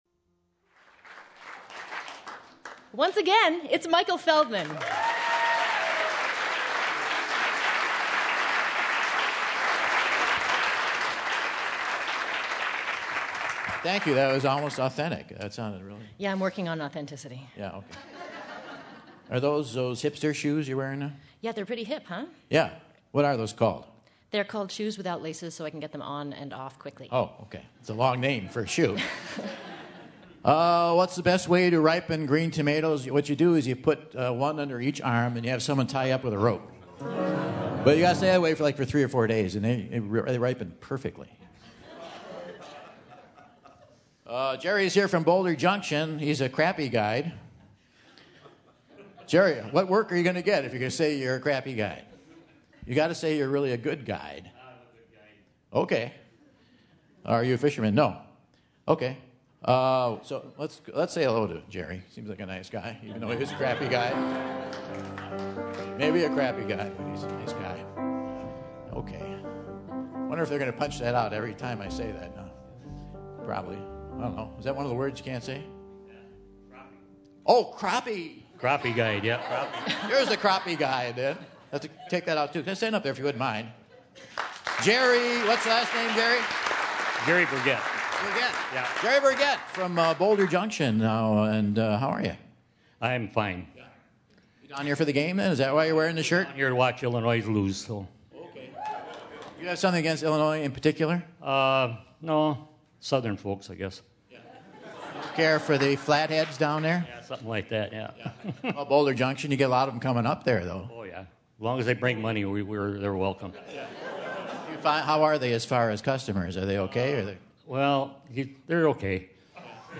After getting a Crappie guide through a "screaming" audience, Michael pulls in his first contestant to play the Whad'Ya Know? Quiz!